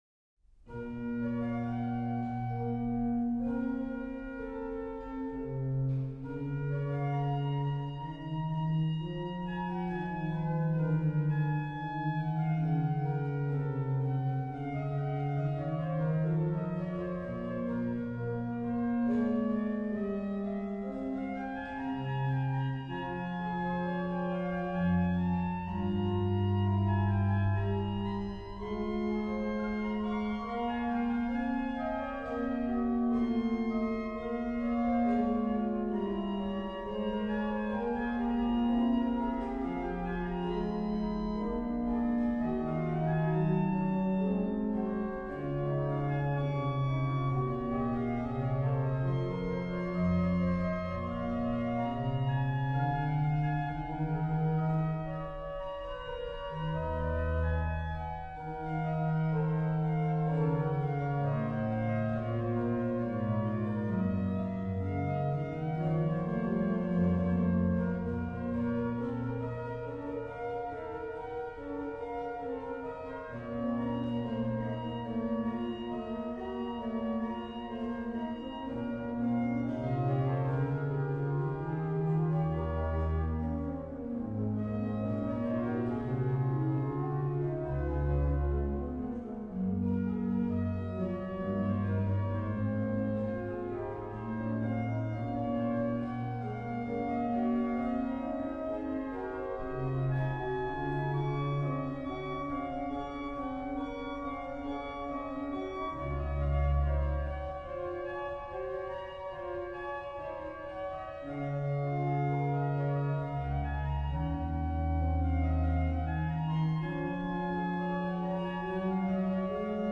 rh: HW: Viol8, Ged8
lh: Pos: Oct8, Rfl8